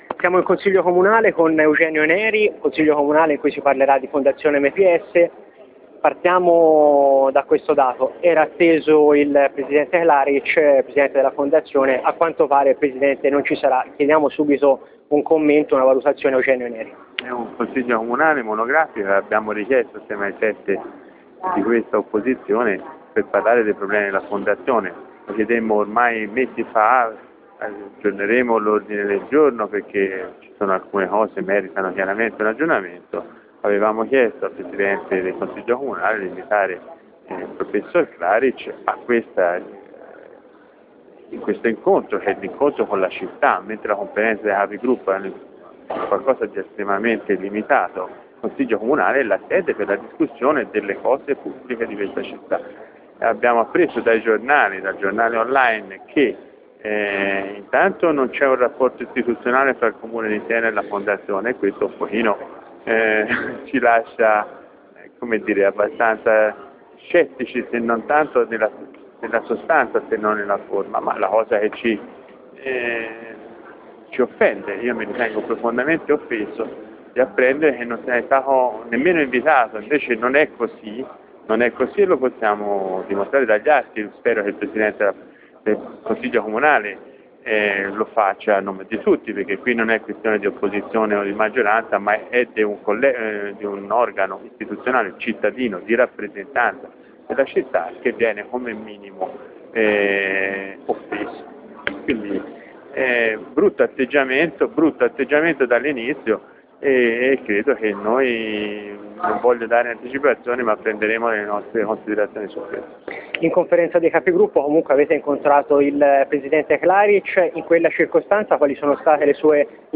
Eugenio Neri in Consiglio Comunale nella seduta monografica sulla Fondazione MPS - Antenna Radio Esse